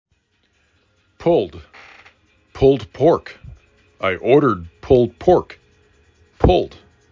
pulled 4 /p/ /u/ /l/ /d/ Frequency: 865
p u l d